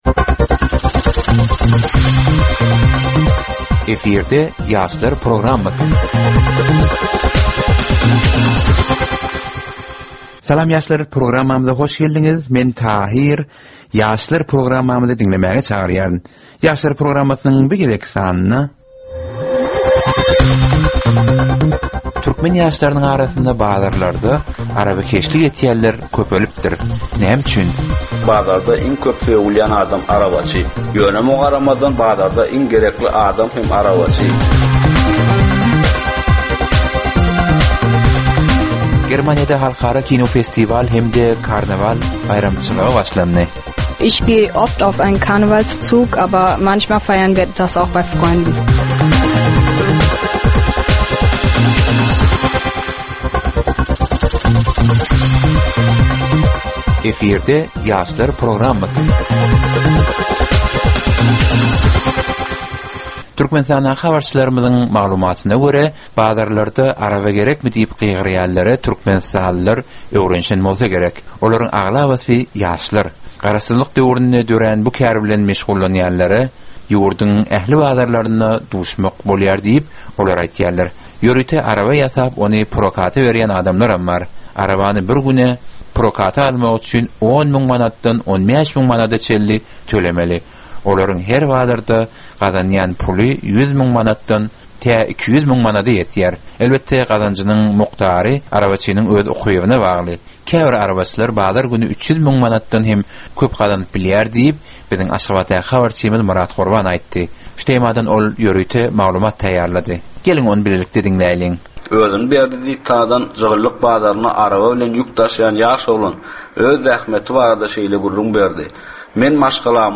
Türkmen we halkara yaşlarynyň durmuşyna degişli derwaýys meselelere we täzeliklere bagyşlanylyp taýýarlanylýan 15 minutlyk ýörite gepleşik. Bu gepleşikde ýaşlaryň durmuşyna degişli dürli täzelikler we derwaýys meseleler barada maglumatlar, synlar, bu meseleler boýunça adaty ýaşlaryň, synçylaryň we bilermenleriň pikirleri, teklipleri we diskussiýalary berilýär. Gepleşigiň dowmynda aýdym-sazlar hem eşitdirilýär.